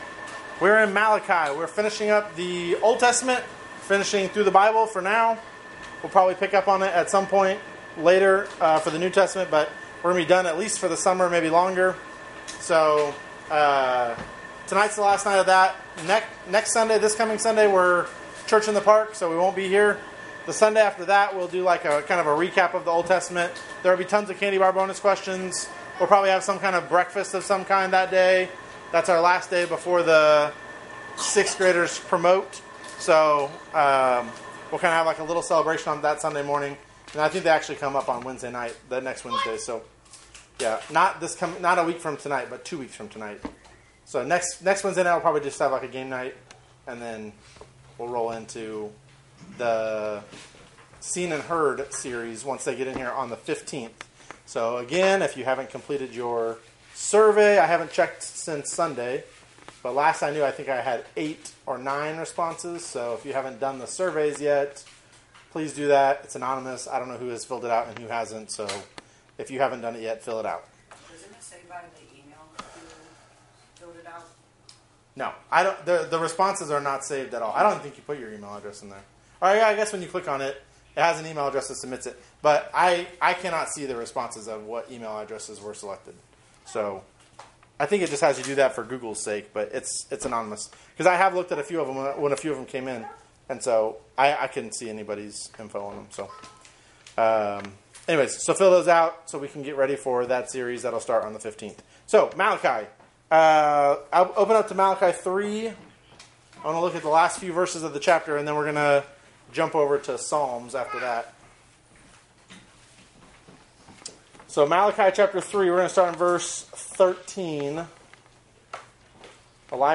Current Sermon
Wednesday Night Bible Study